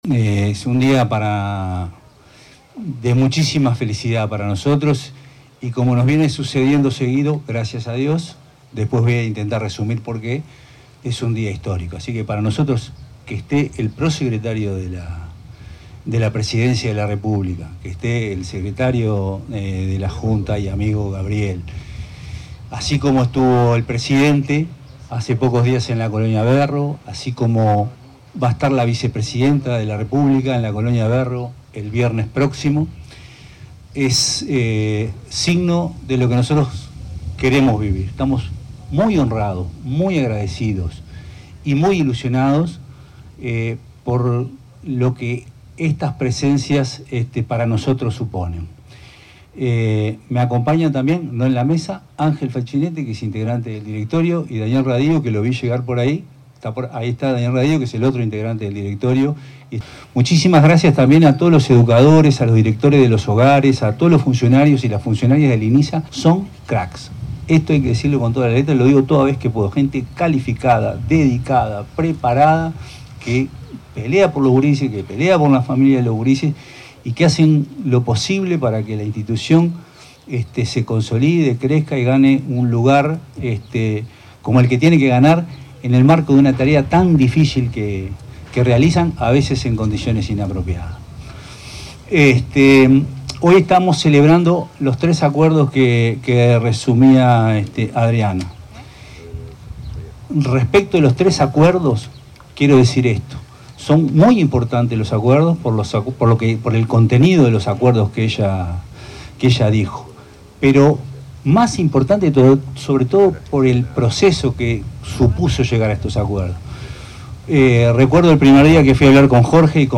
Palabras de autoridades representantes de la Junta Nacional de Drogas y del Inisa